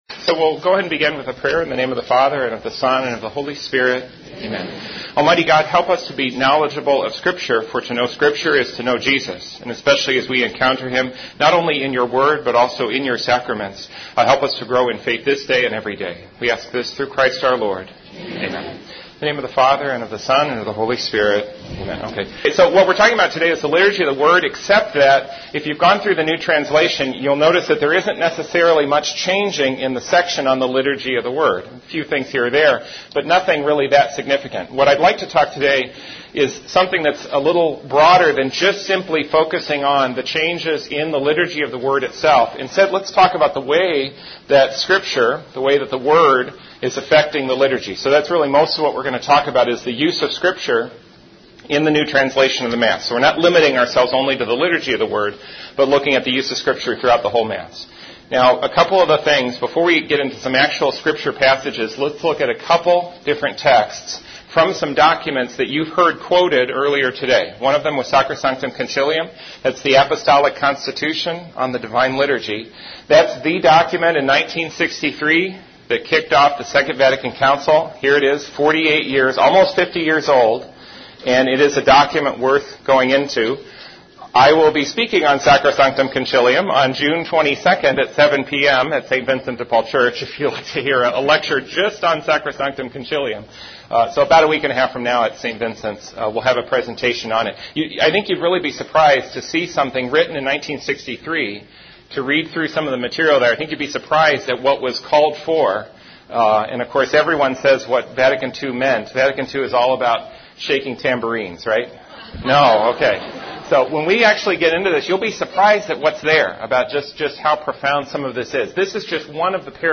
The following presentation was given as part of the Summer Catechetical Institute in the Diocese of Peoria on June 11, 2011. The focus of the presentation was on elements of Scripture that are present in the Mass, and especially in the new translation to be implemented in November, 2011.